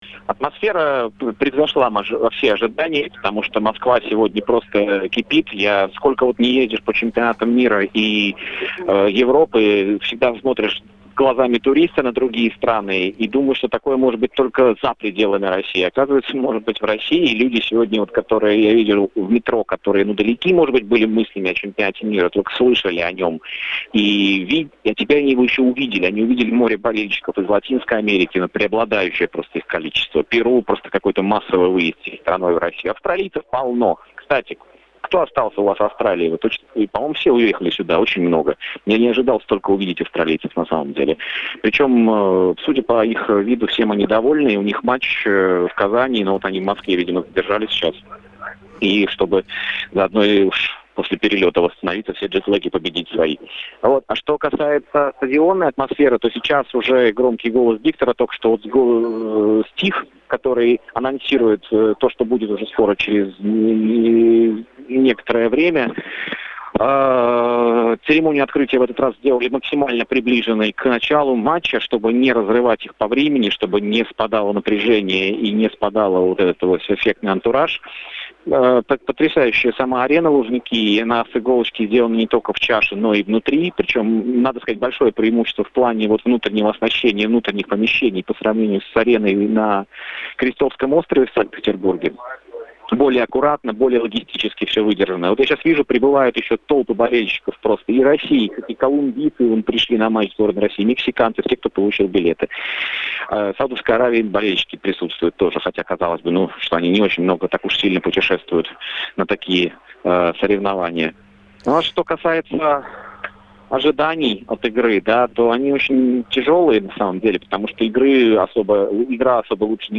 перед началом матча Россия-Саудовская Аравия со стадиона "Лужники"